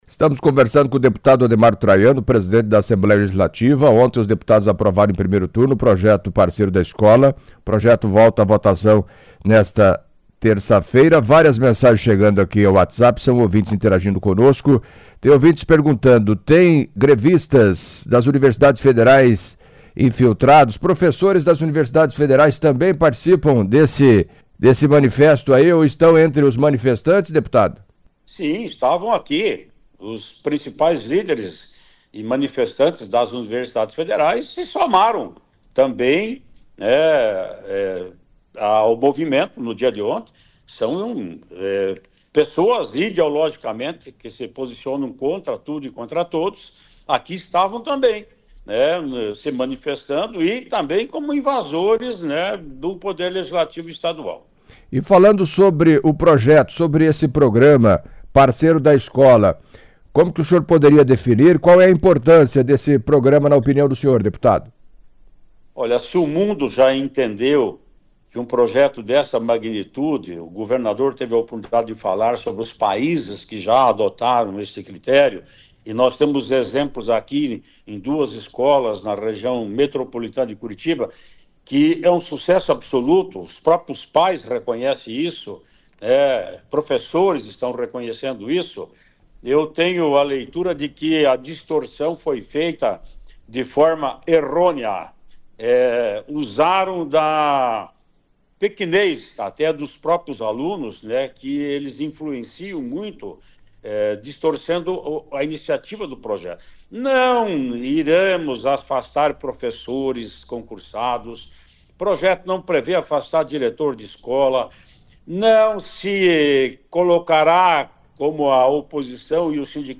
Em entrevista à CBN Cascavel nesta terça-feira (4) o deputado Ademar Traiano, presidente da Assembleia Legislativa, fez duras críticas ao comportamento da APP SIndicato e professores que são contrários ao projeto "Parceiro da Escola", que está sendo apreciado e votado na Alep, e ressaltou que a invasão e a forma como os manifestantes agiram, o desfecho será na Justiça.